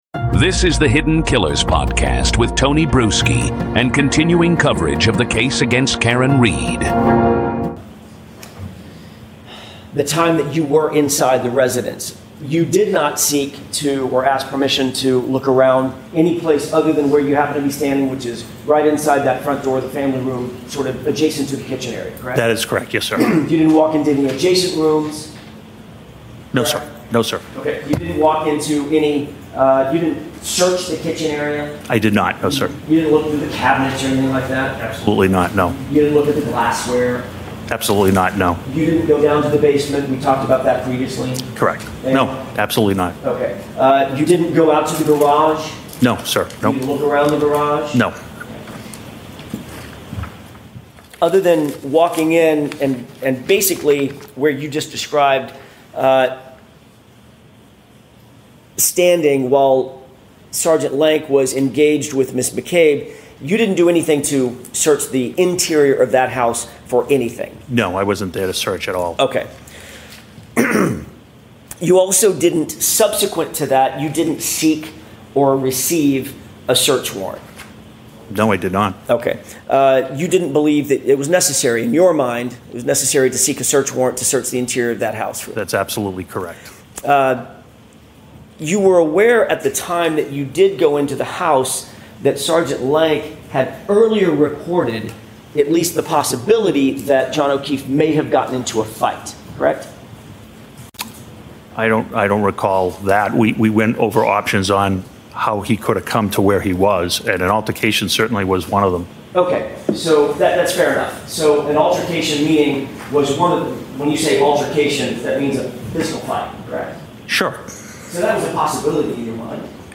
Raw Testimony